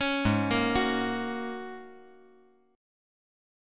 Ab9sus4 Chord
Listen to Ab9sus4 strummed